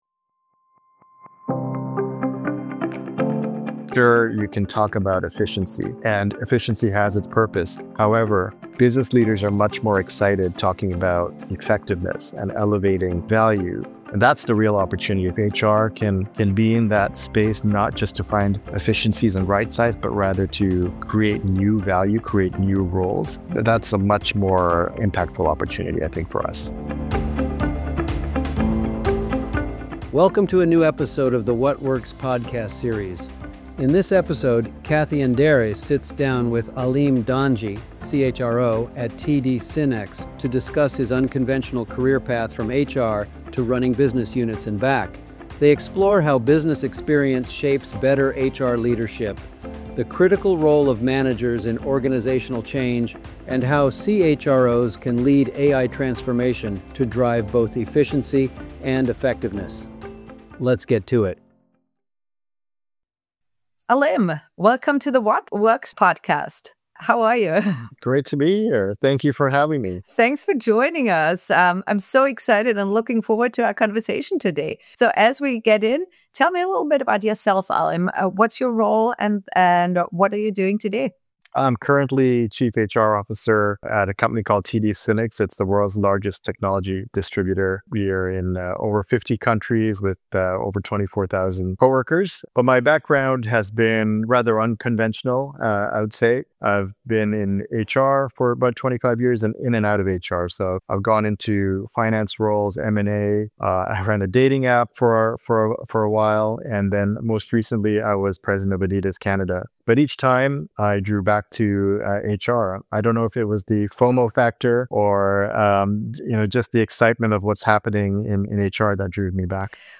This is a fascinating discussion about the value drivers of HR and AI and the process for redesigning work.